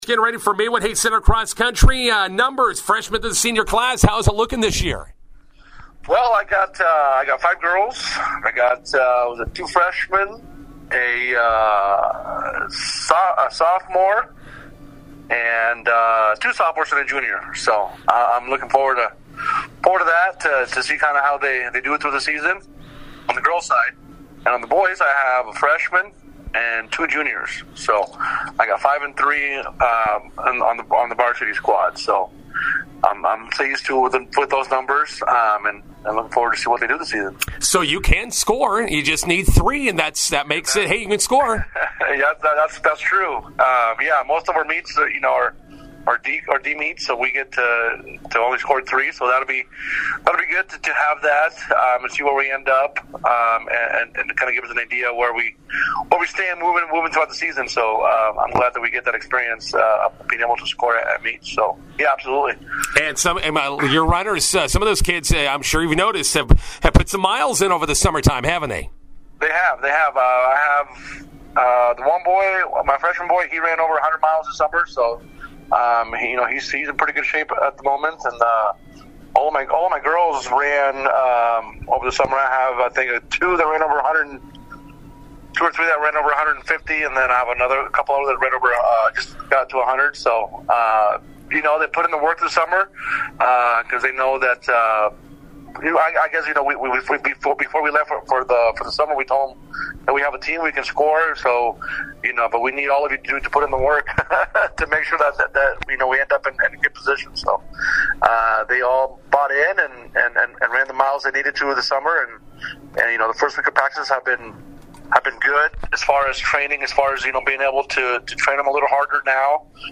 INTERVIEW: Maywood-Hayes Center cross country runners open season Thursday at DCS Invite.